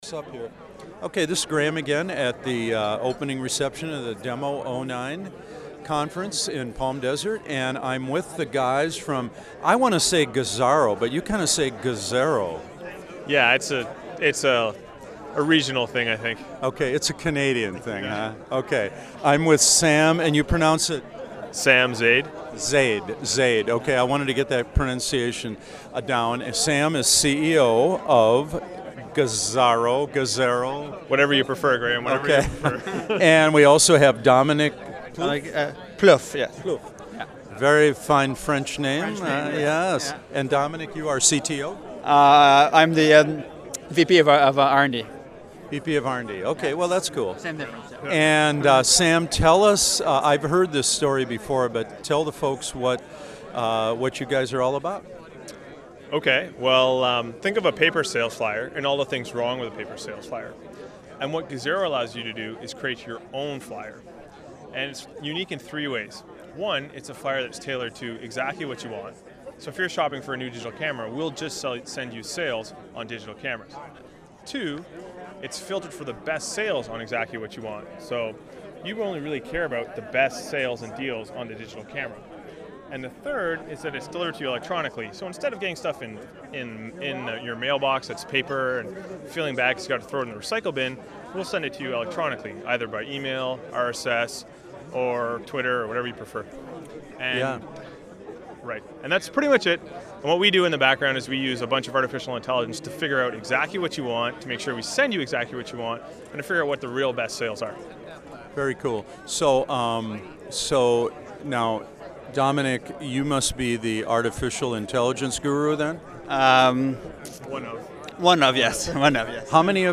DEMO 09 – Opening Reception, Interview 6: Gazaro
CA. One of the presenting companies I ran into as the party started to wind down was Gazaro, based in Ottawa, Canada.